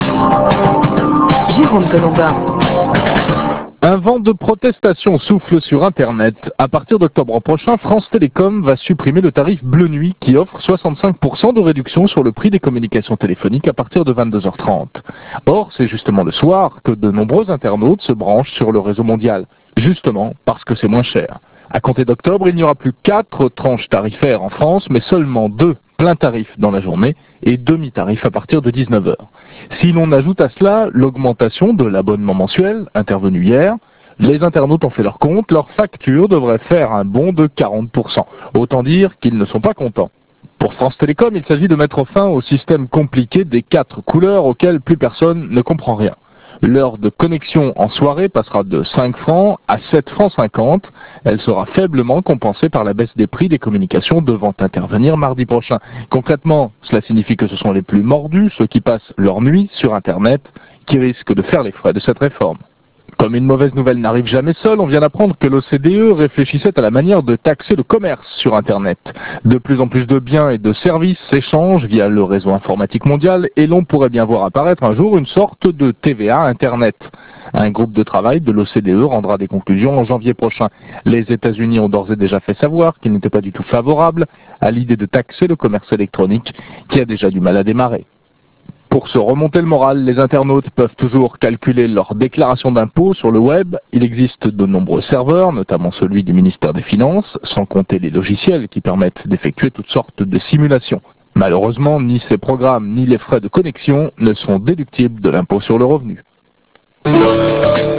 NetFlash sur France Info ( 2/02/97 )